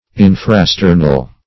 Search Result for " infrasternal" : The Collaborative International Dictionary of English v.0.48: Infrasternal \In`fra*ster"nal\, a. [Infra + sternal.]
infrasternal.mp3